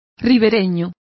Complete with pronunciation of the translation of riverside.